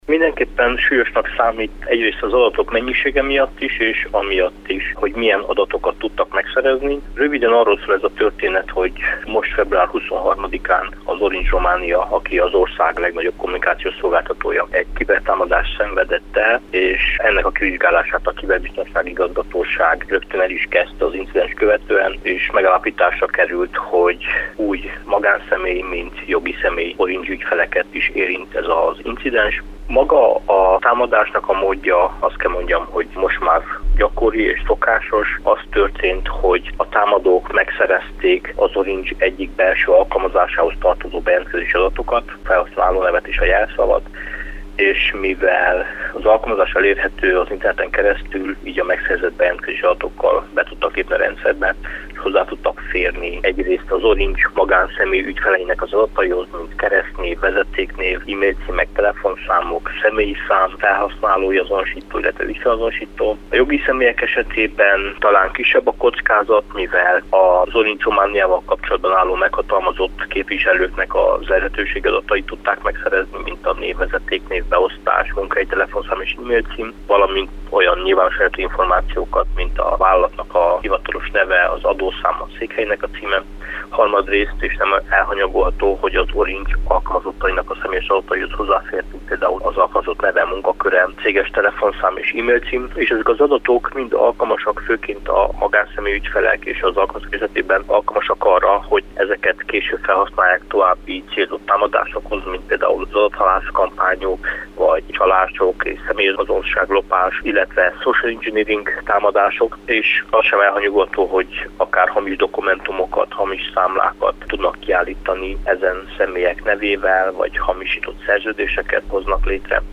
Élő Kolozsvári Rádió